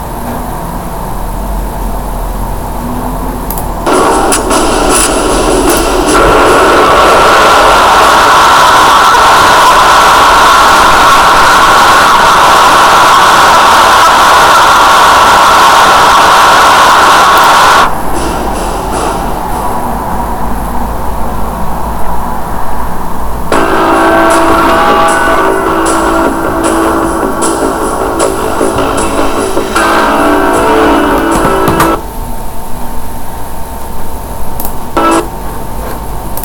Counter Strike 1.6 - trzeszczący mikrofon
Nagrania są z menu Counter Strike-a , nie z serwera jakby co.
Mikrofon - Mikrofon wewnętrzny w laptopie